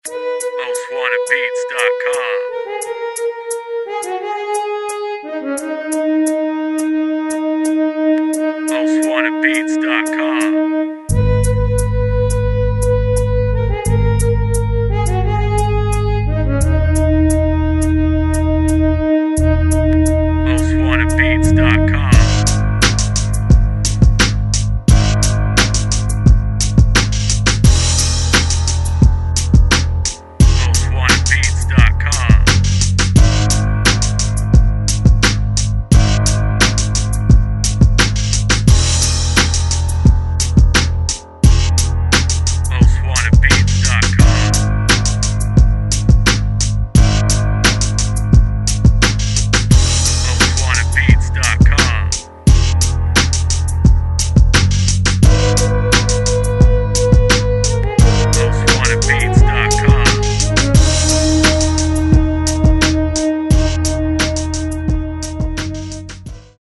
EAST COAST INSTRUMENTAL